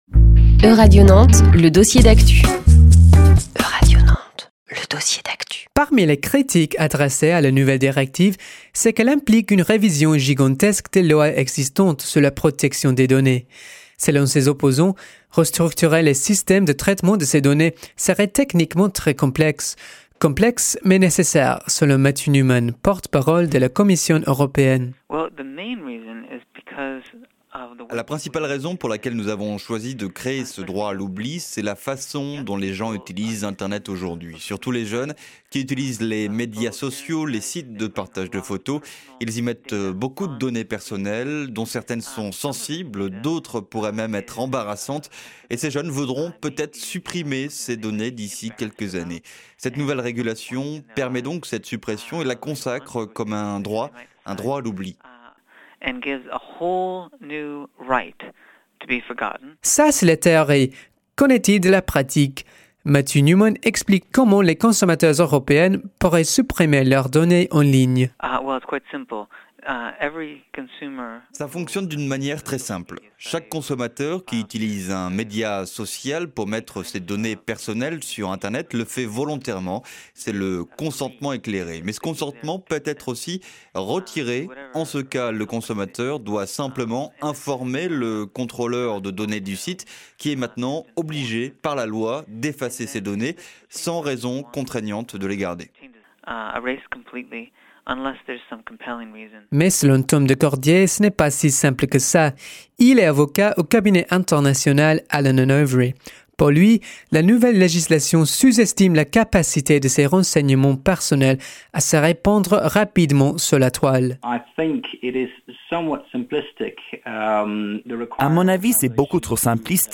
Recorded for Euradio Nantes.